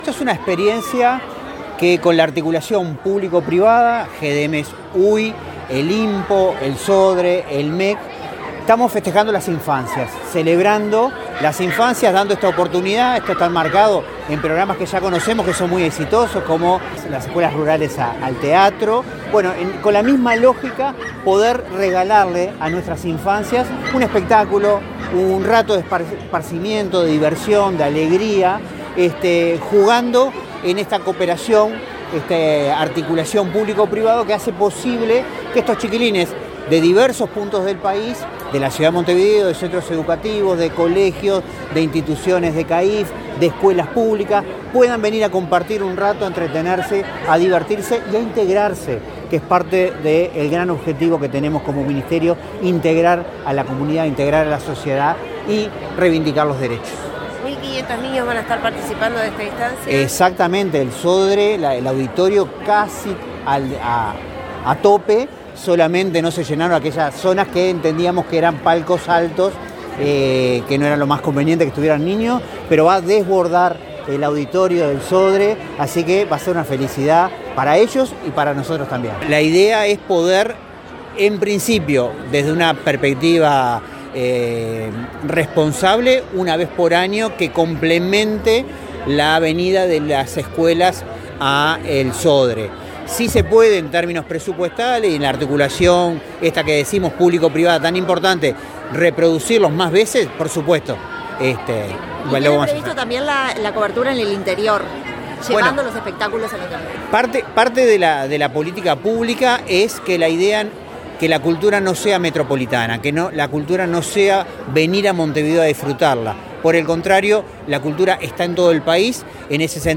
Declaraciones del director general del MEC, Carlos Varela
Declaraciones del director general del MEC, Carlos Varela 18/08/2025 Compartir Facebook X Copiar enlace WhatsApp LinkedIn Con motivo de la representación de la obra de teatro “Superniña” en el auditorio del Sodre, destinada a 1.500 niños de centros educativos y otras dependencias públicas, el director general del Ministerio de Educación y Cultura (MEC), Carlos Varela, efectuó declaraciones a la prensa.